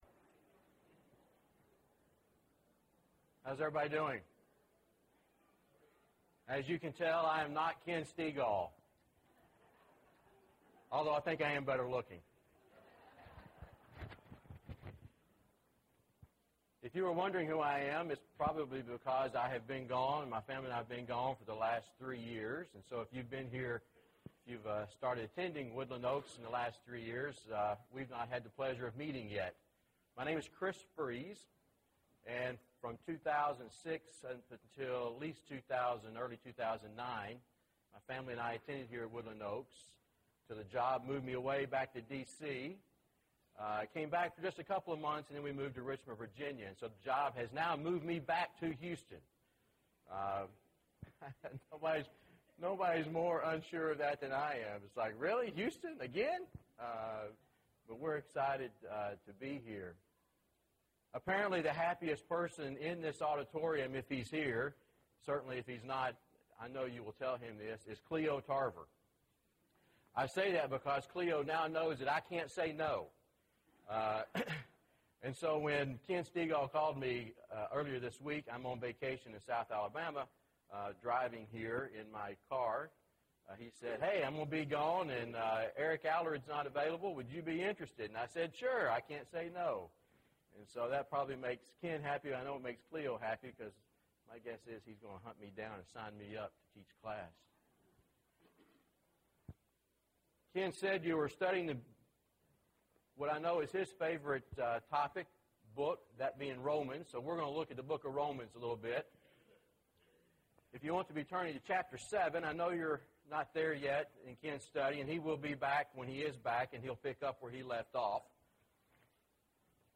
Romans – (Guest Speaker) – Bible Lesson Recording
Sunday AM Bible Class